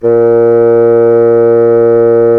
Index of /90_sSampleCDs/Roland LCDP04 Orchestral Winds/WND_Bassoons/WND_Bassoon 1
WND BSSN A#2.wav